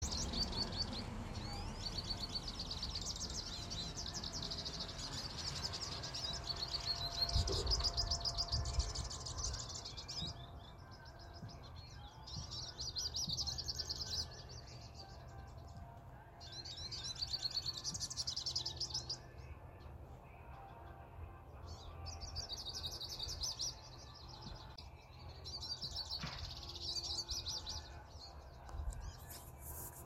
Hooded Siskin (Spinus magellanicus)
Condition: Wild
Certainty: Observed, Recorded vocal